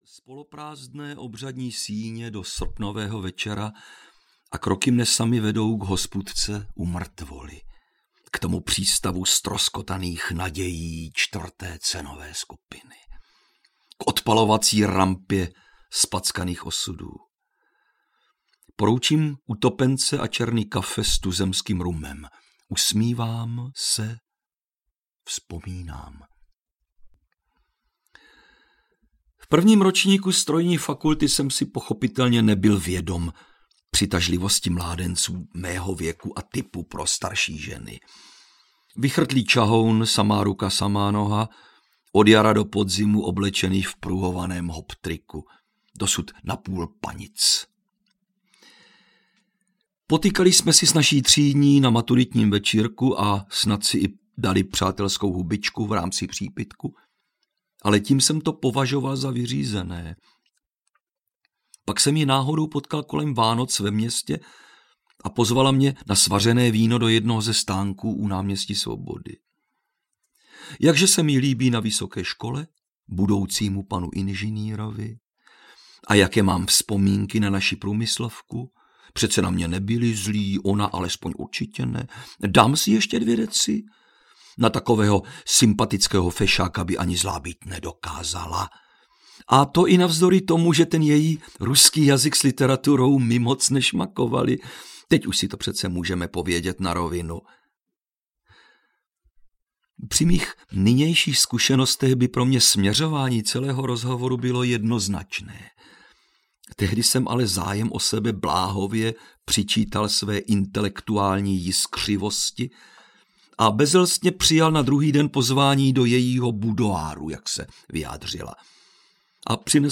Aspoň zavři hubu, když se divíš audiokniha
Ukázka z knihy